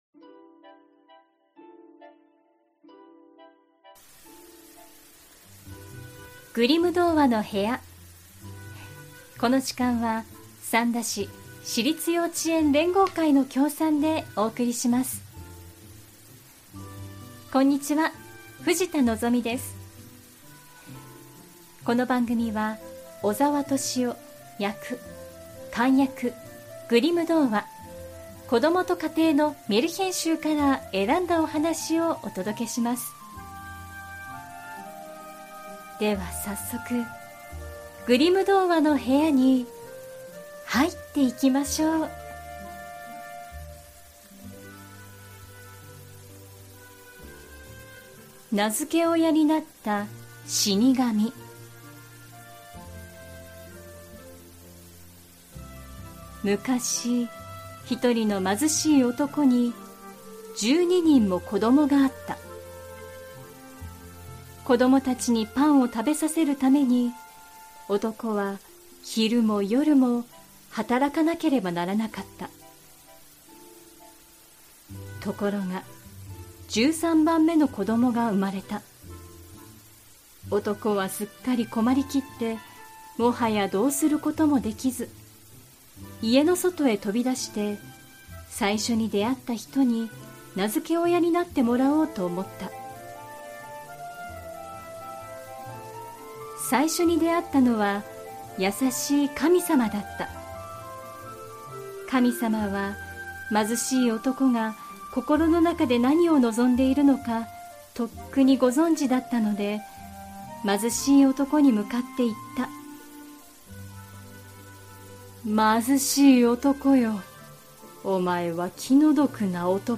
グリム兄弟によって集められたメルヒェン（昔話）を、翻訳そのままに読み聞かせします📖今回お届けするのは『名付け親になった死神』。